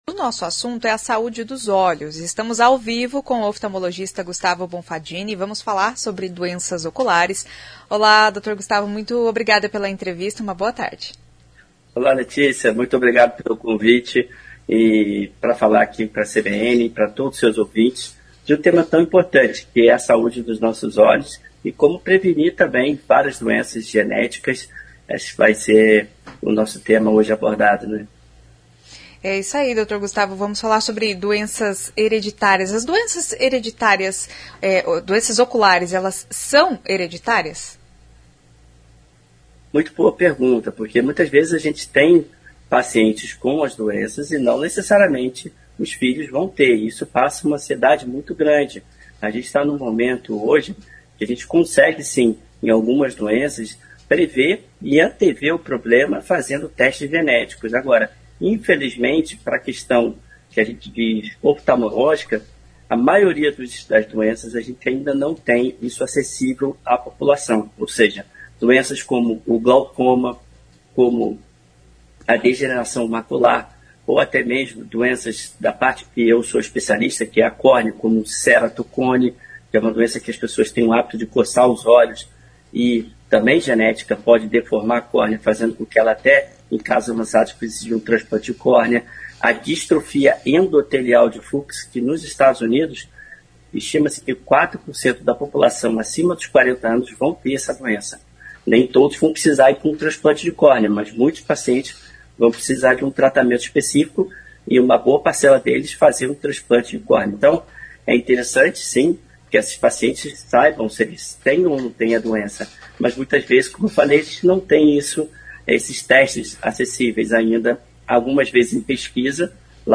A prevenção é a melhor alternativa para o tratamento precoce ou até para evitar a doença. Ouça a entrevista.